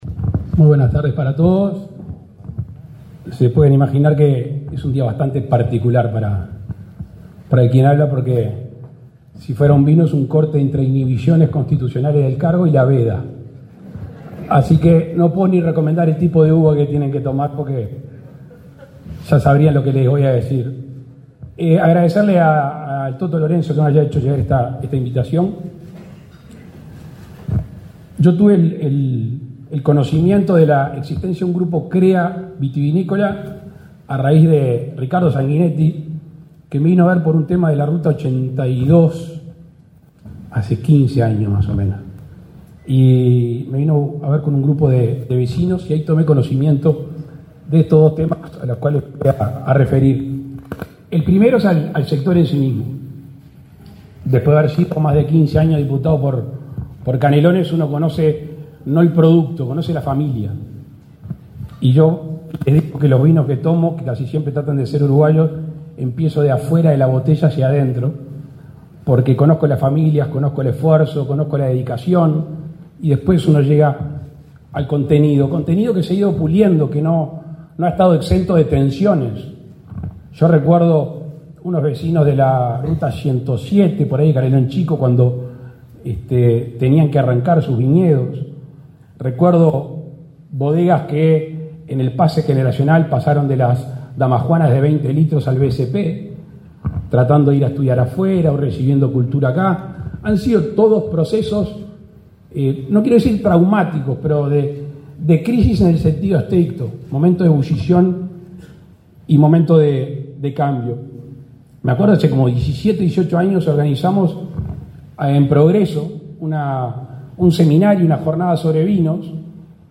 Palabras del presidente de la República, Luis Lacalle Pou
Palabras del presidente de la República, Luis Lacalle Pou 25/10/2024 Compartir Facebook X Copiar enlace WhatsApp LinkedIn El presidente de la República, Luis Lacalle Pou, participó, este 25 de octubre, en el 50.° aniversario del Grupo Crea-Vitivinicultura. En el evento, el mandatario realizó declaraciones.